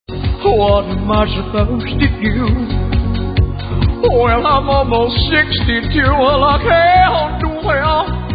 Vocal impressions of Sinatra, cher, and Rodney Dangerfield.
Cher-Impersonator-Impressionist-Comedy-Corporate-Entertainment.mp3